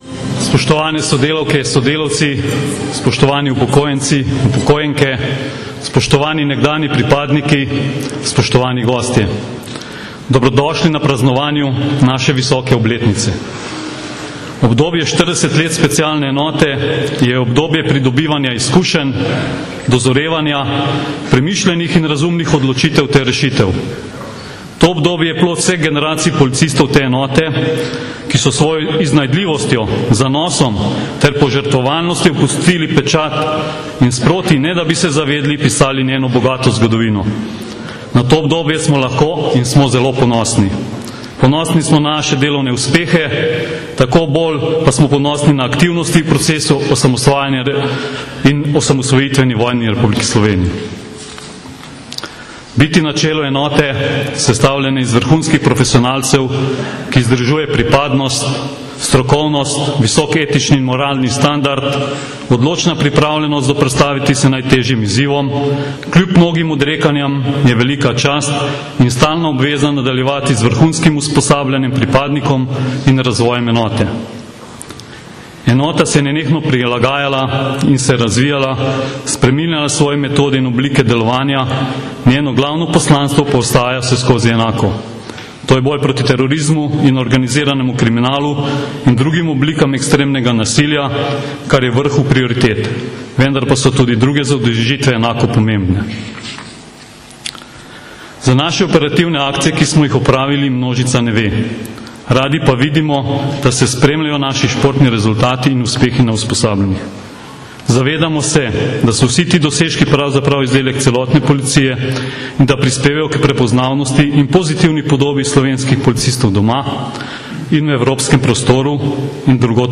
Slovesno praznovanje je bilo 22. maja 2013 v Policijski akademiji v Tacnu v Ljubljani.
Pozdravni nagovor poveljnika Specialne enote Marjana Anzeljca  (velja govorjena beseda)
Zvočni posnetek pozdravnega nagovora Marjana Anzeljca (mp3)